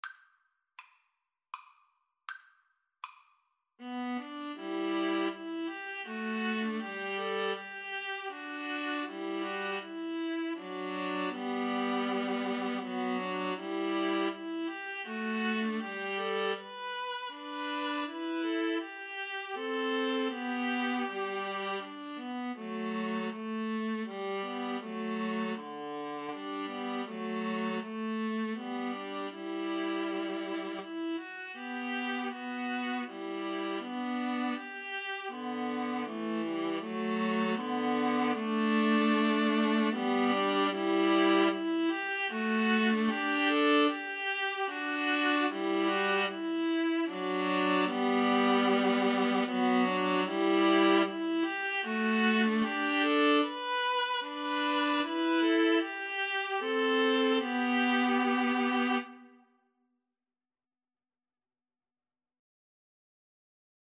Play (or use space bar on your keyboard) Pause Music Playalong - Player 1 Accompaniment Playalong - Player 3 Accompaniment reset tempo print settings full screen
G major (Sounding Pitch) (View more G major Music for String trio )
3/4 (View more 3/4 Music)
Andante
String trio  (View more Easy String trio Music)
Classical (View more Classical String trio Music)